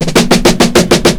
FILL 1    -L.wav